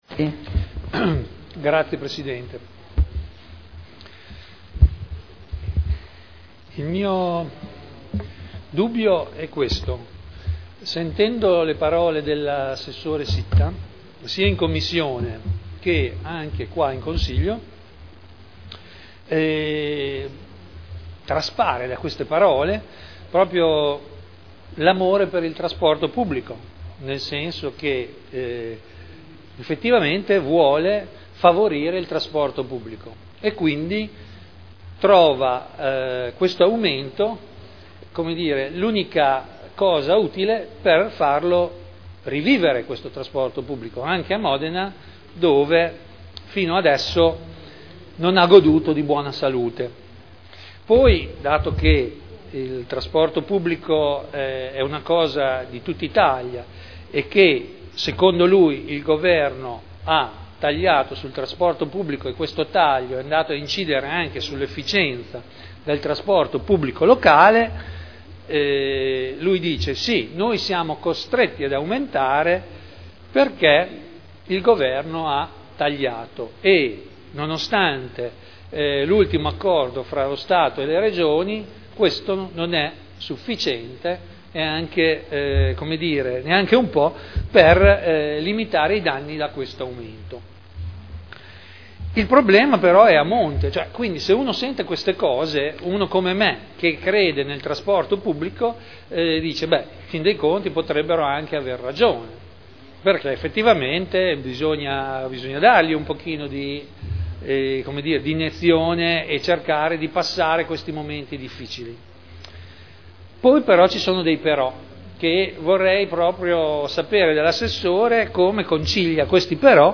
Seduta del 21/02/2011. Indirizzi per la gestione del trasporto pubblico locale a seguito del patto per il trasporto pubblico regionale e locale in Emilia Romagna per il triennio 2011/2013 – aumenti tariffari per il Comune di Modena – Approvazione discussione